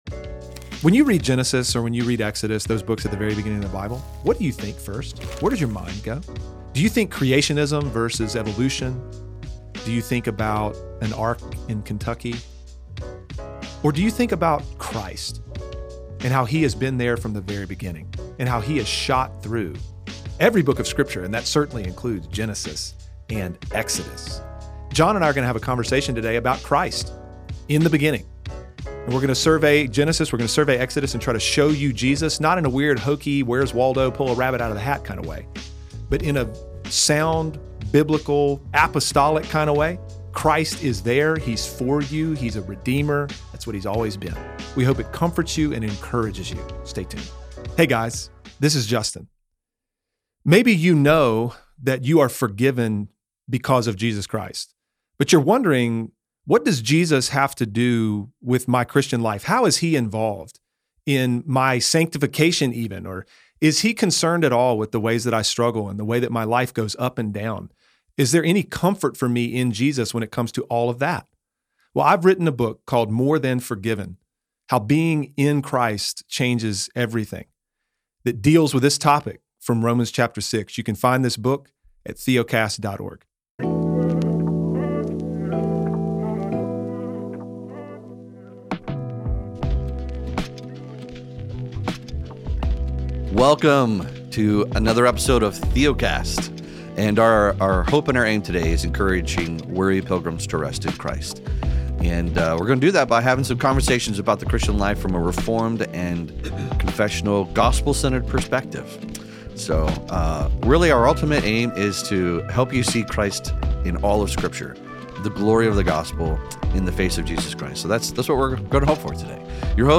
Our primary focus at Theocast is to encourage weary pilgrims to rest in Christ. We facilitate simple conversations about the Christian life from a reformed perspective through our weekly podcasts, primers, books, articles, and educational material.